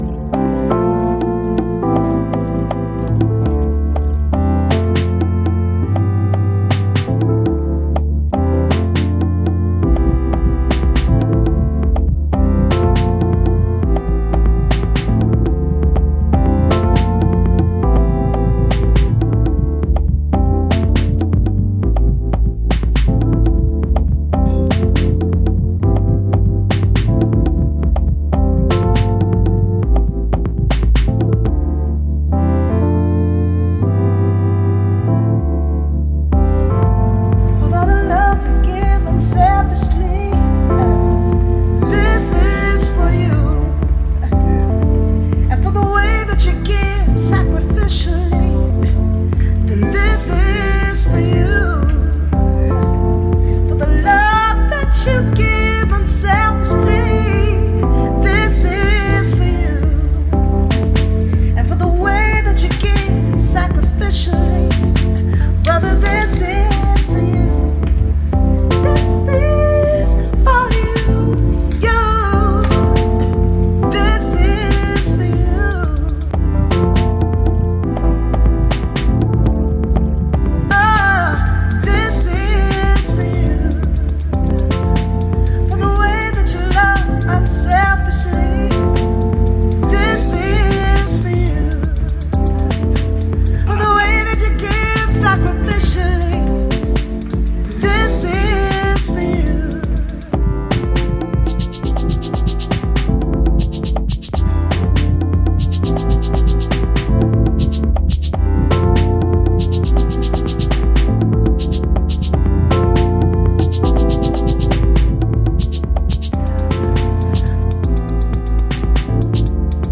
jazz e funk